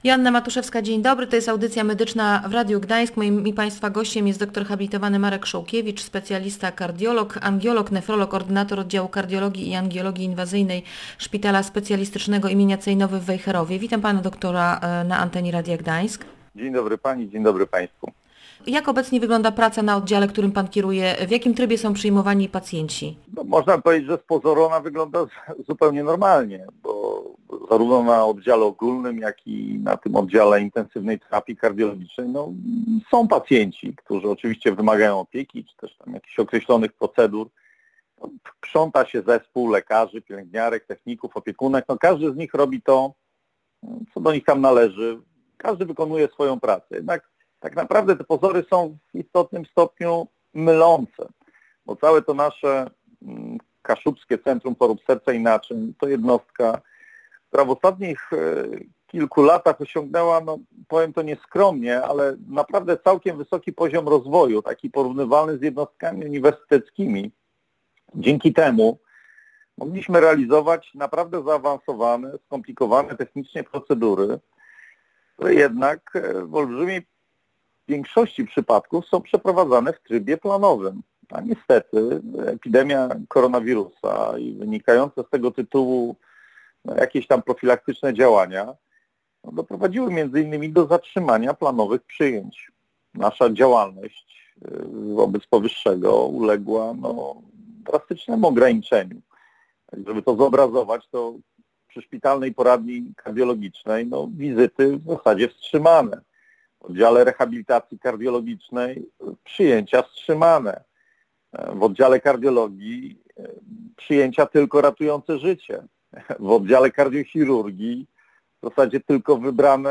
mówił specjalista w rozmowie